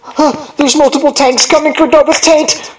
mvm_tank_alerts10.mp3